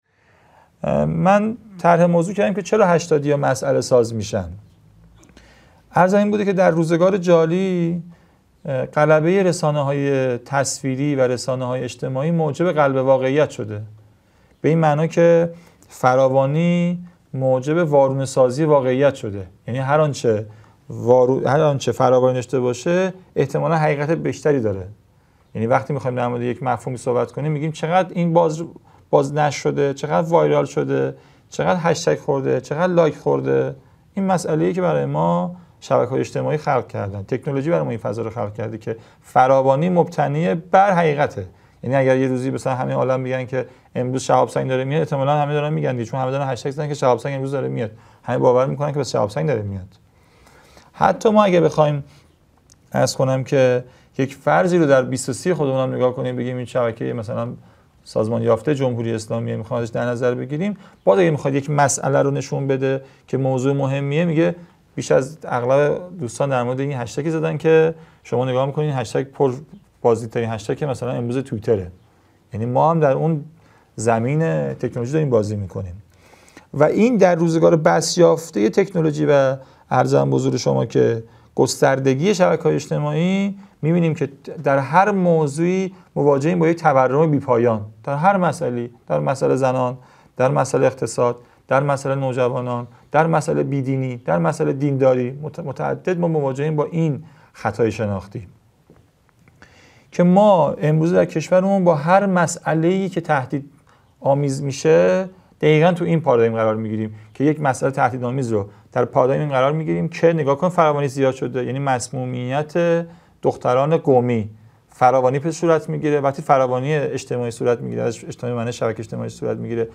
مدرسه عالی هیأت | گزیده سوم از دومین سلسله نشست‌ های هیأت و نوجوانان